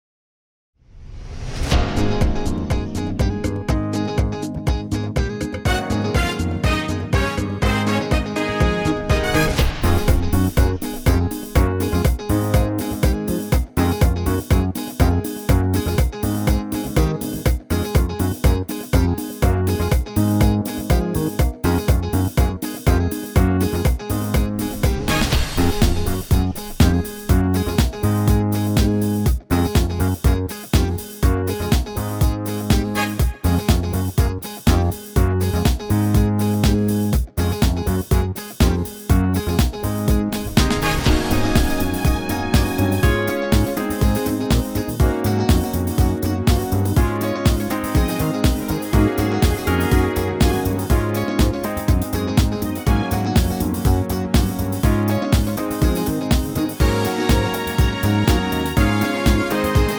key - Db - vocal range - F to Ab
Suitable for high male ranges and lower female.